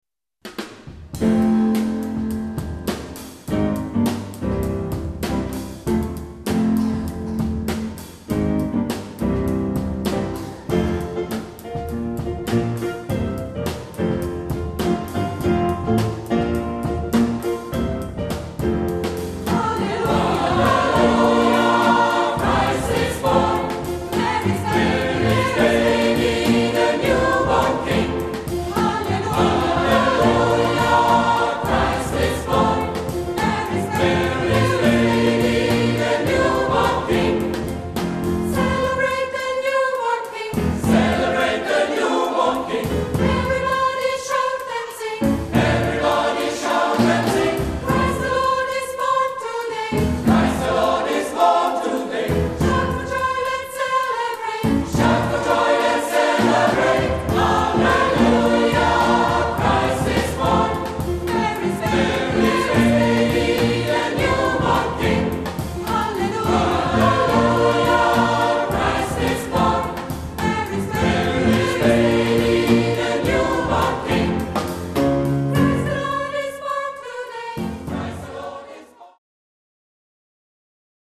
Pop- und Gospelchor